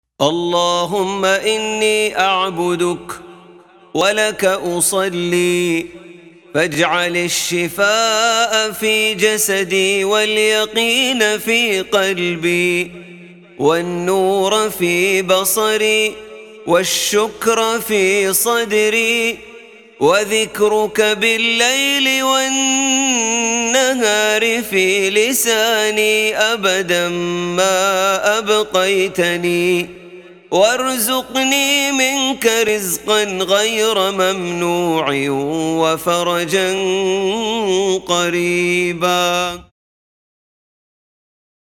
دعاء خاشع يتضرع فيه العبد لربه طالباً الشفاء لبدنه واليقين لقلبه والنور لبصره. يجمع الدعاء بين طلب العافية الجسدية والمعنوية، مع التذكير بنعم الله وشكره، وطلب الرزق وتفريج الكرب.